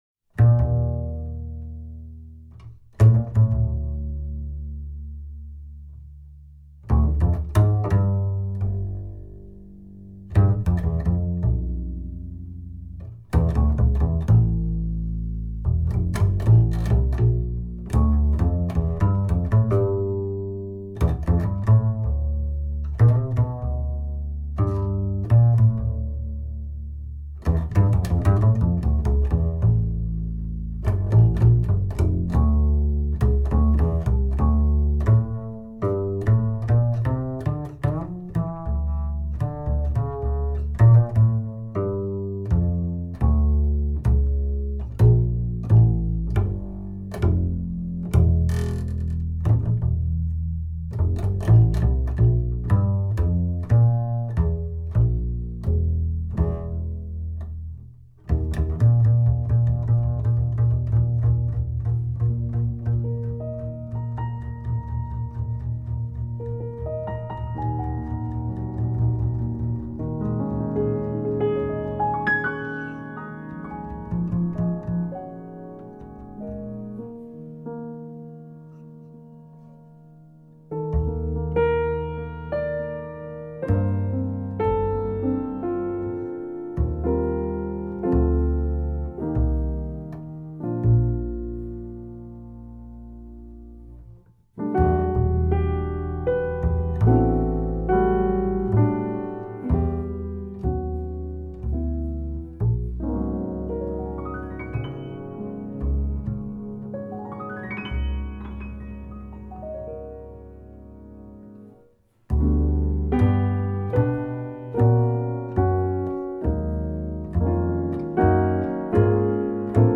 contrabbasso
piano
sax tenore